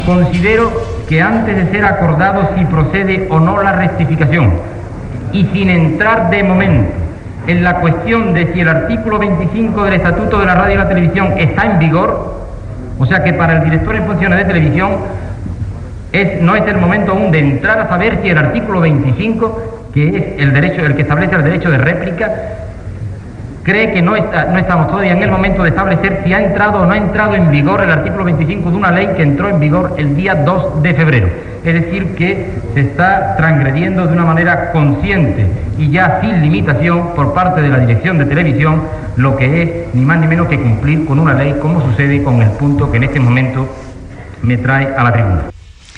Crítica del diputat socialista Alfonso Guerra, al Congrés, sobre el Director General en funcions de RTVE Luis Ezcurra
Informatiu